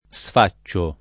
vai all'elenco alfabetico delle voci ingrandisci il carattere 100% rimpicciolisci il carattere stampa invia tramite posta elettronica codividi su Facebook sfare v.; sfaccio [ S f #©© o ] (meno com. sfo [ S f 0+ ]), sfai — coniug. come fare